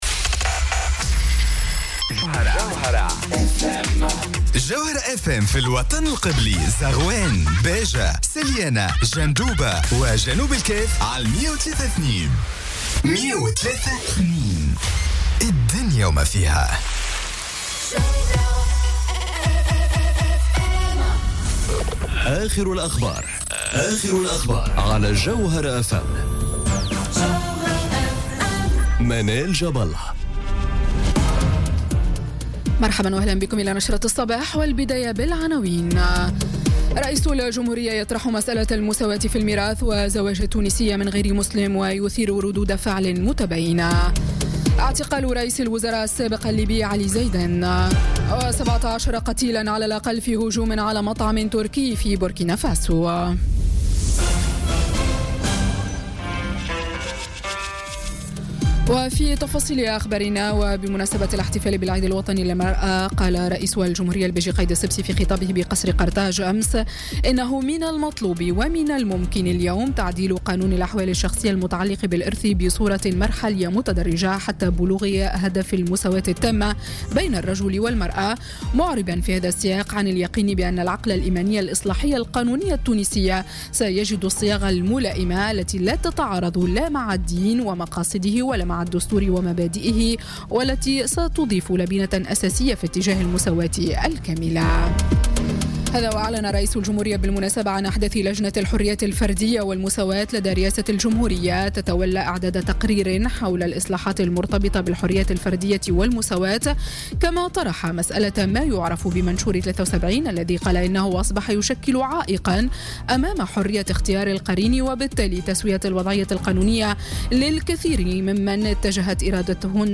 نشرة أخبار السابعة صباحا ليوم الاثنين 14 أوت 2017